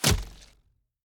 Bow Impact Hit 1.ogg